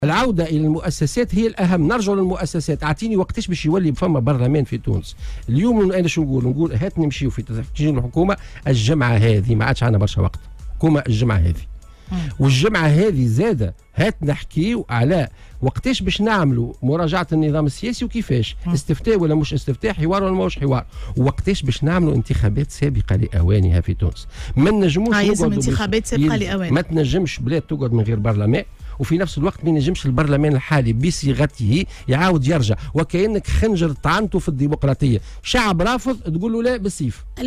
ودعا في مداخلة له اليوم على "الجوهرة أف أم" إلى تشكيل الحكومة خلال الأسبوع الحالي وإلى مراجعة النظام السياسي وتنظيم انتخابات سابقة لأوانها في تونس.